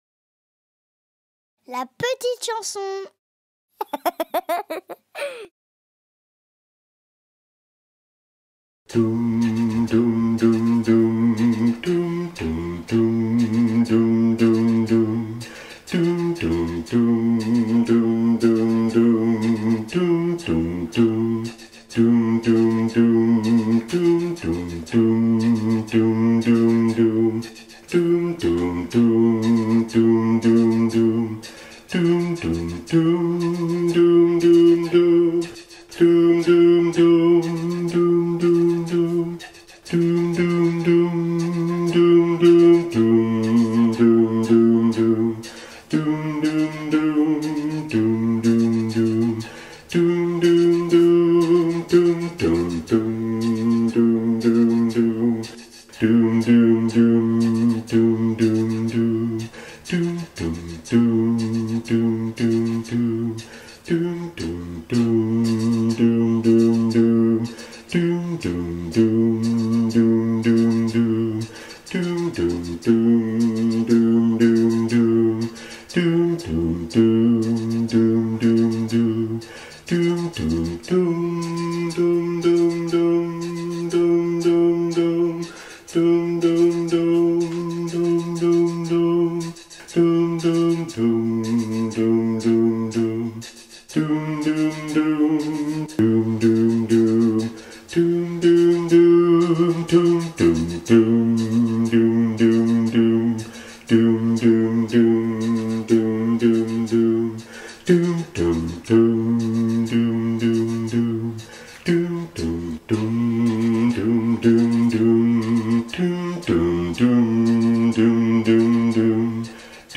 MP3 versions chantées
A 3 Voix Mixtes Voix 3 Basse